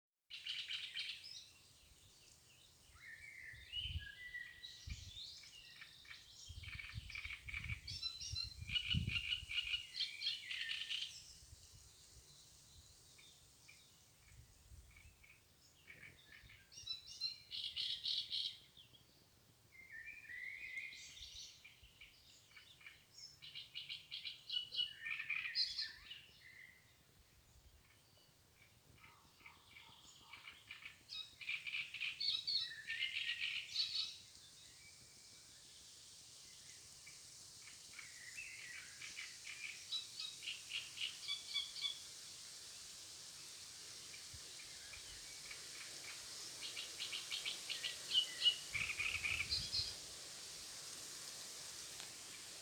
Птицы -> Славковые ->
дроздовидная камышевка, Acrocephalus arundinaceus
Administratīvā teritorijaIkšķiles novads
СтатусПоёт